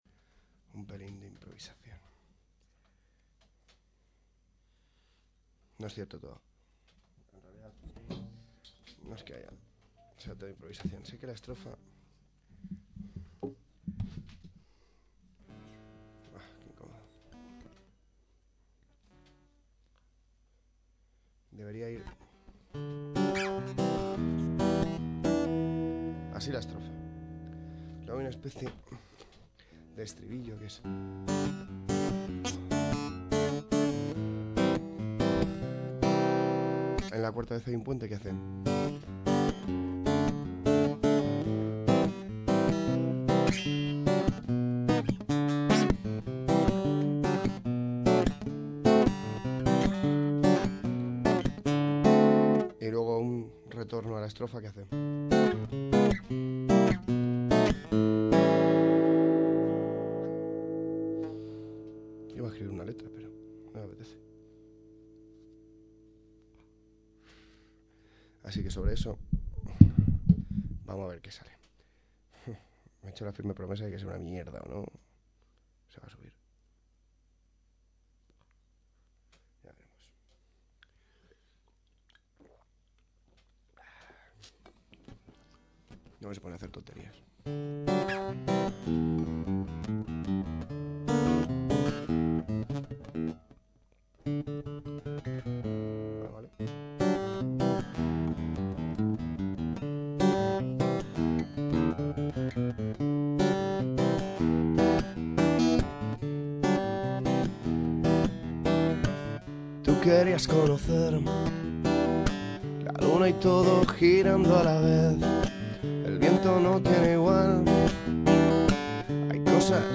No está mal improvisar cuando no tienes ganas de escribir la letra.